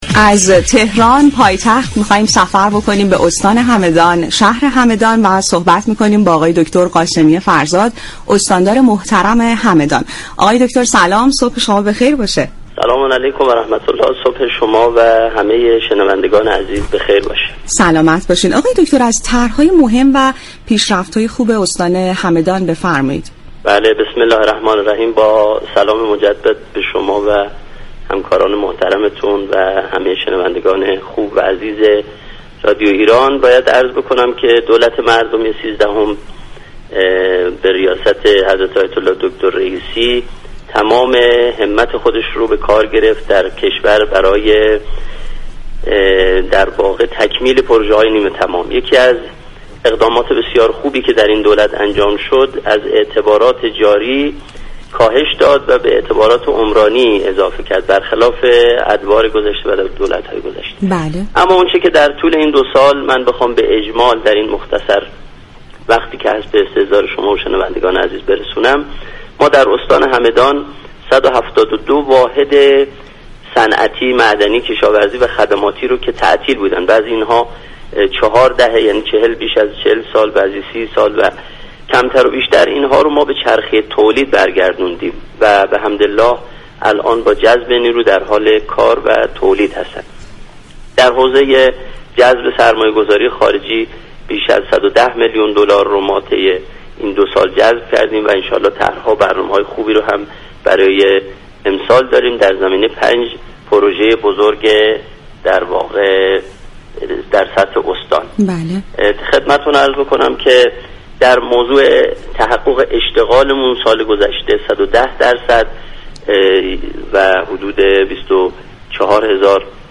علیرضا قاسمی فرزاد، استاندار همدان در گفت و گو با برنامه «سلام صبح بخیر» رادیو ایران از تكمیل پروژه های نیمه تمام استان خبر داد.